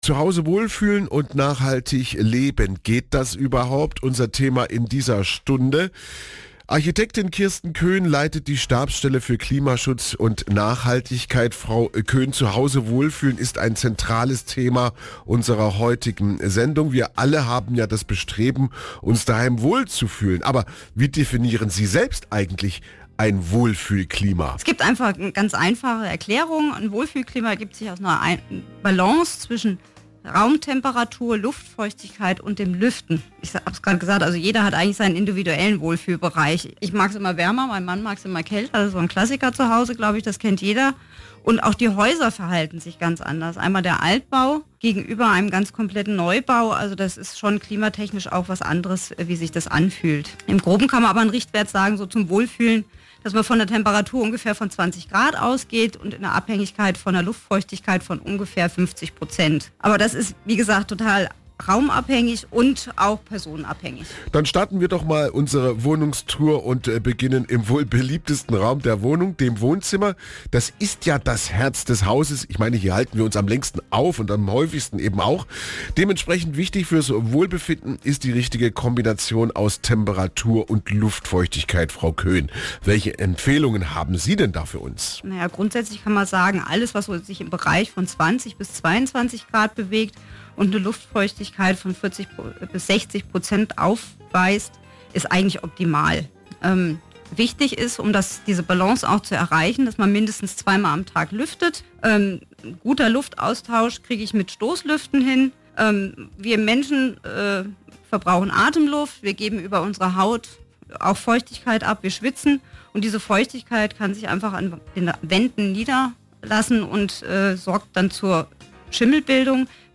Radio Sondersendung – „Zu Hause wohlfühlen und nachhaltig Leben. Geht das überhaupt?“